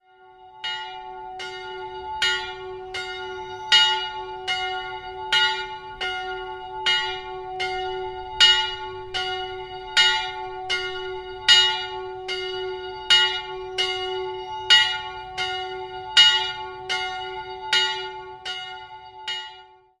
Einzelglocke fis'' Die Glocke wurde 1762 von Christian Victor Herold in Nürnberg gegossen.